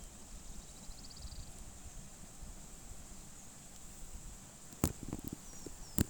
Asthenes wyatti
Nome em Inglês: Streak-backed Canastero
Localidade ou área protegida: El Infiernillo
Condição: Selvagem
Certeza: Gravado Vocal